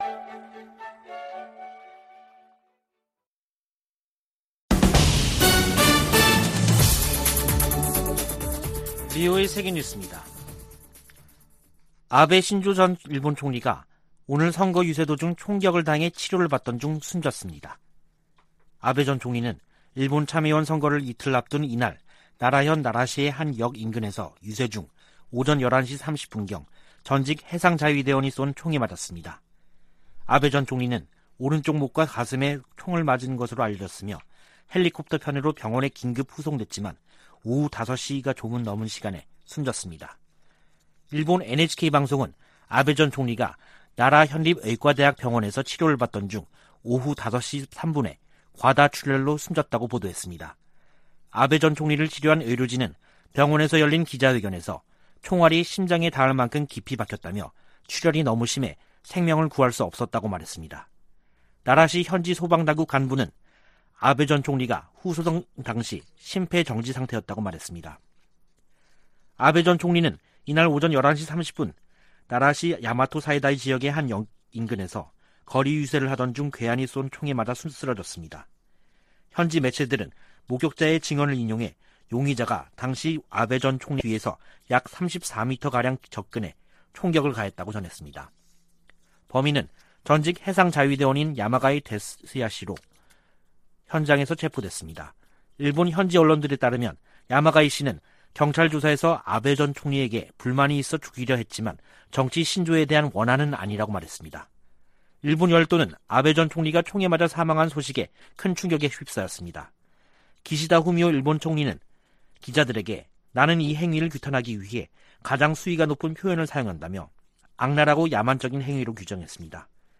VOA 한국어 간판 뉴스 프로그램 '뉴스 투데이', 2022년 7월 8일 3부 방송입니다. 미국과 한국, 일본이 G20 외교장관회의를 계기로 3국 외교장관 회담을 열고 북한 문제 등을 논의했습니다. 미의회에서는 북핵 문제를 넘어 미한일 공조를 강화하려는 움직임이 두드러지고 있습니다. 미 국무부의 데릭 촐렛 특별보좌관이 조태용 주미 한국대사를 만나 미한일 협력 강화 방안 등을 논의했습니다.